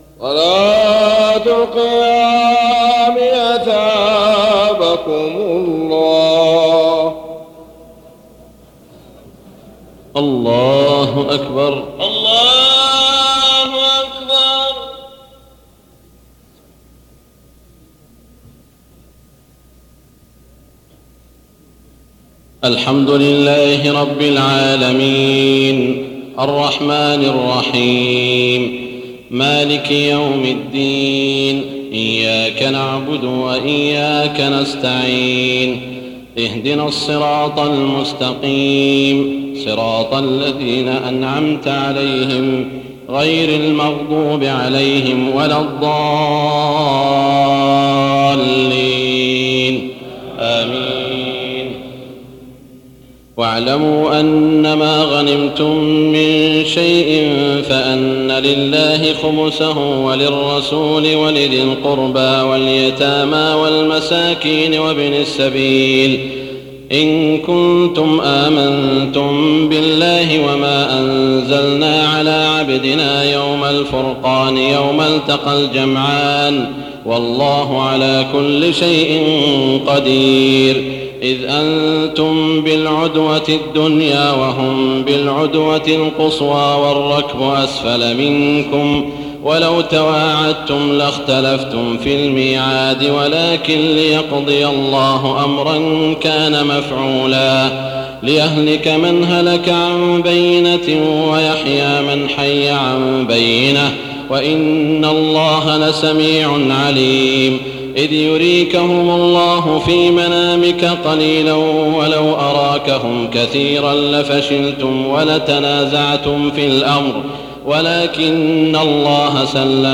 تراويح ليلة 30 رمضان 1419هـ من سورتي الأنفال (41-75) و التوبة (1-33) Taraweeh 30 st night Ramadan 1419H from Surah Al-Anfal and At-Tawba > تراويح الحرم المكي عام 1419 🕋 > التراويح - تلاوات الحرمين